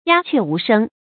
注音：ㄧㄚ ㄑㄩㄝˋ ㄨˊ ㄕㄥ
鴉雀無聲的讀法